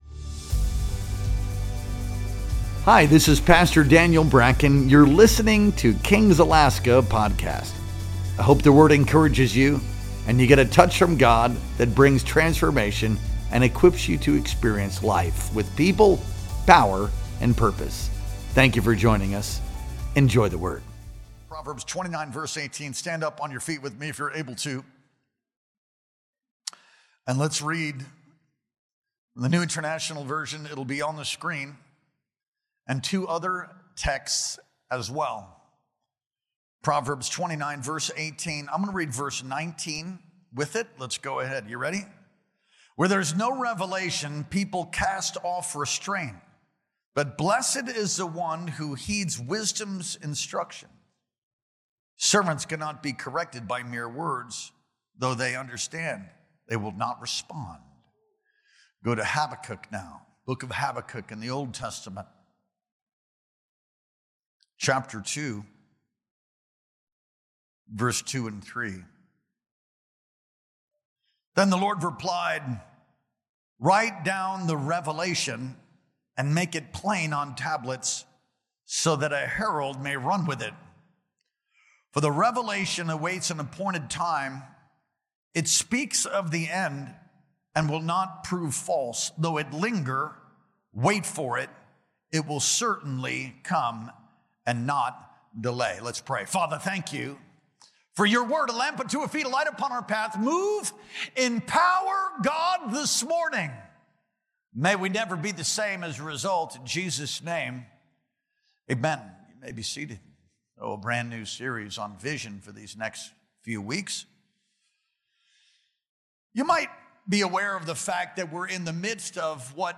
Our Sunday Morning Worship Experience streamed live on October 12th, 2025.